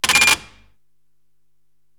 Register Talk.wav